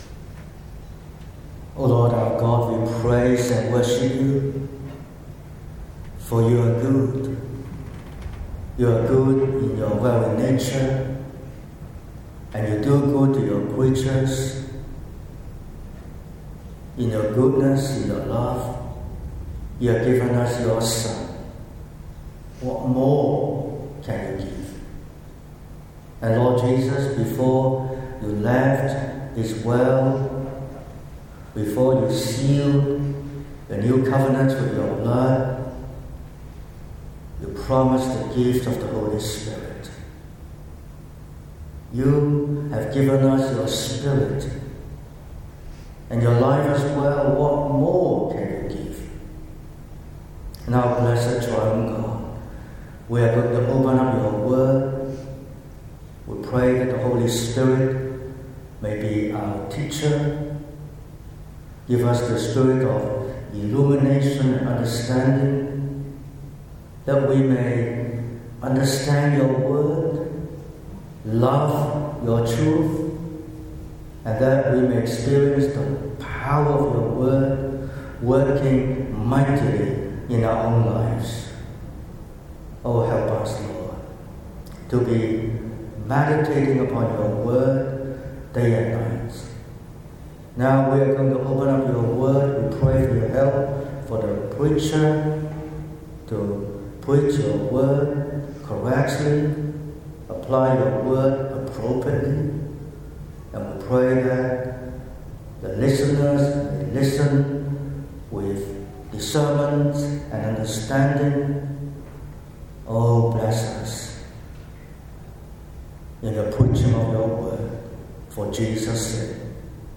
12/10/2025 – Evening Service: Judge, lawgiver, king – Isaiah 33:22
Sermon Outline